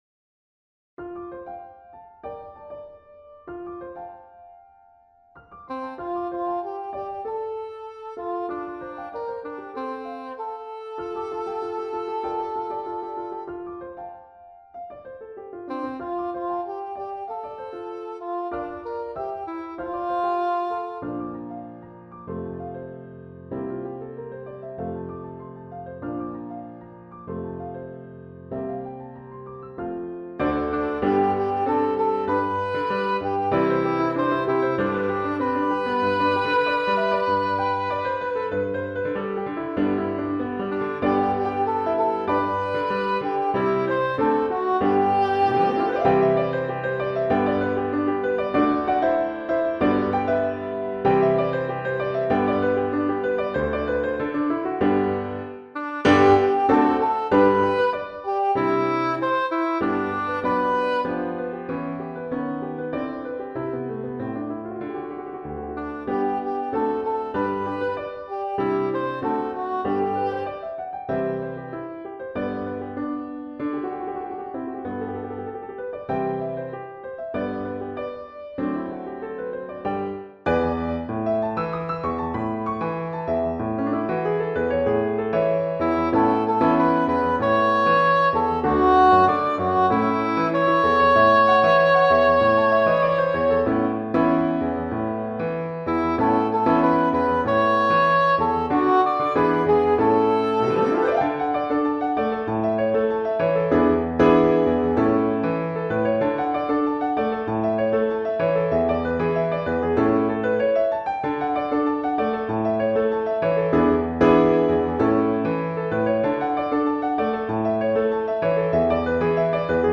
Chorale d'Enfants et Piano